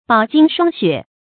饱经霜雪 bǎo jīng shuāng xuě 成语解释 饱：充分；经：经历；霜雪：比喻艰难困苦。